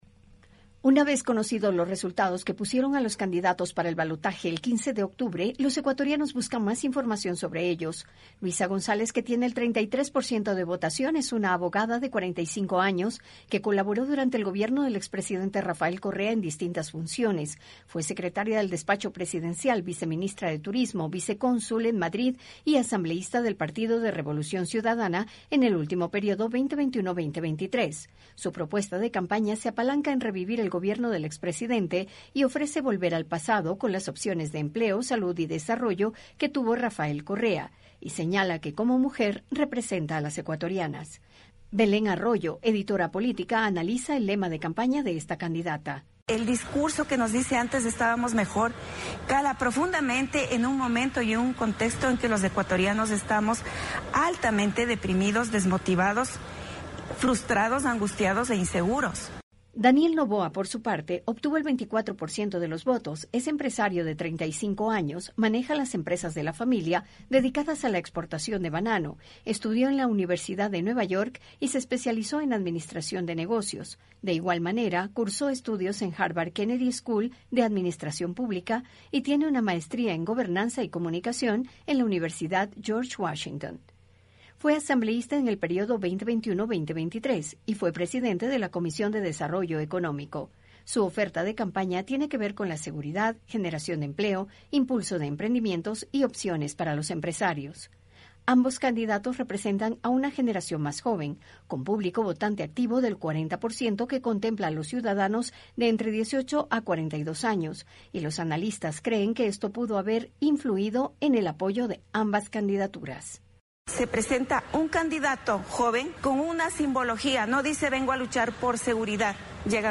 Los votantes ecuatorianos se interesan por conocer los perfiles de sus candidatos que pasan a segunda vuelta y el apoyo que tendrán en la Asamblea Nacional. El informe